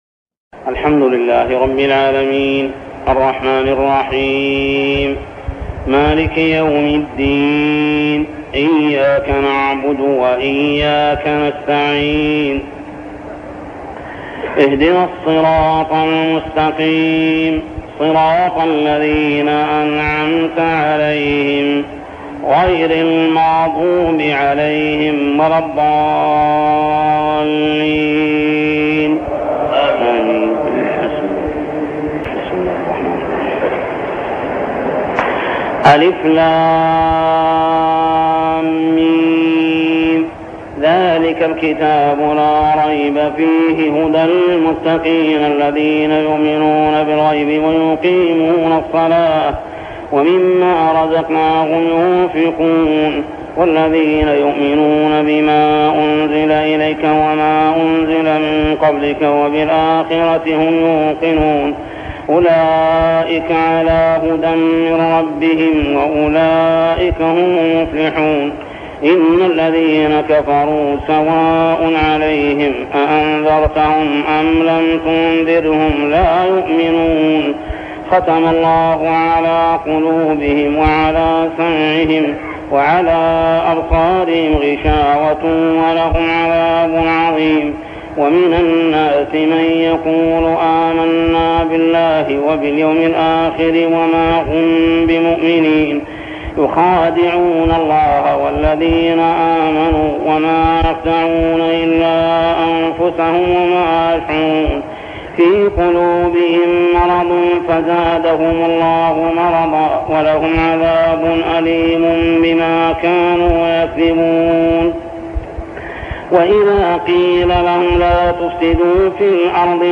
صلاة التراويح عام 1403هـ سورتي الفاتحة كاملة و البقرة 1-55 ( الآيات 56-74 مفقودة ) | Tarawih prayer Surah Al-Fatihah and Al-Baqarah > تراويح الحرم المكي عام 1403 🕋 > التراويح - تلاوات الحرمين